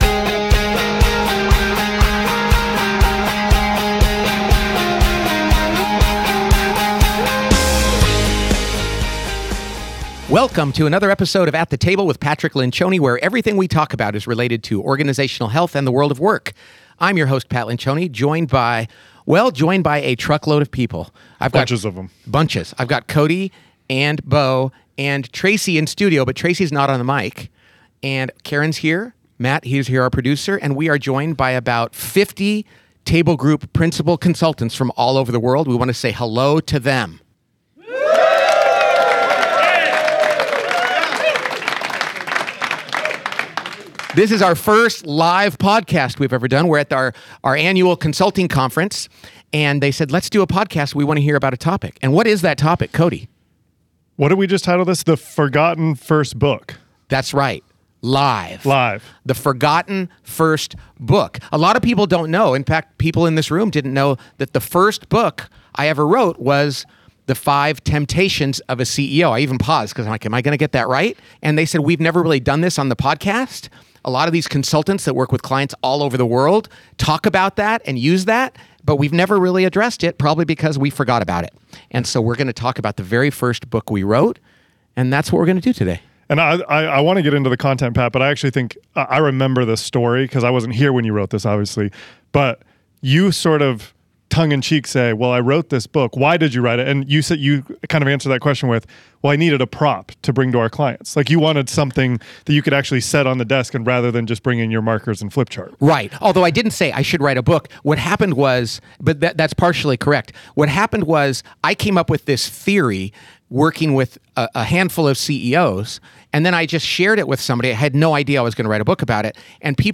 This week, the entire podcast team discusses Pat's first book - The Five Temptations of a CEO - and takes questions from a live audience of Table Group Principal Consultants.